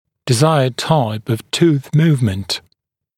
[dɪ’zaɪəd taɪp əv tuːθ ‘muːvmənt][ди’зайэд тайп ов ту:с ‘му:вмэнт]желаемый тип перемещение зуба (-ов)